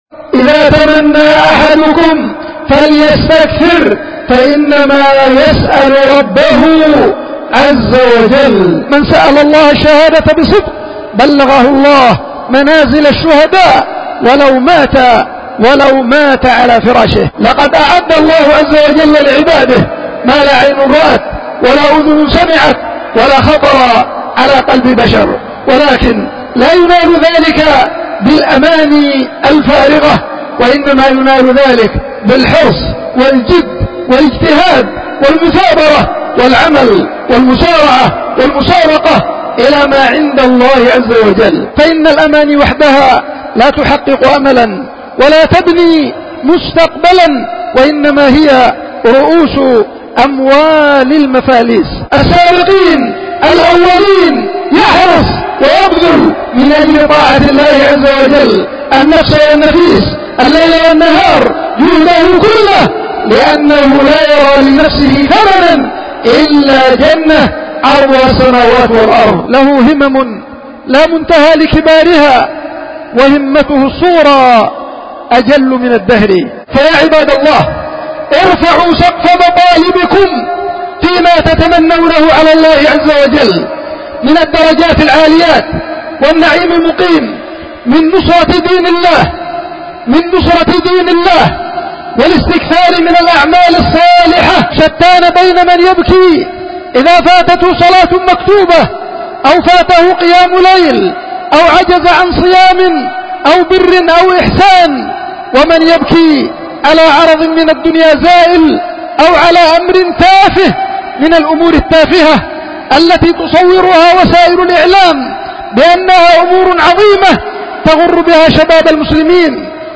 خطبة الجمعة
القيت في دار الحديث في مدينة دار السلام العلمية بيختل المخا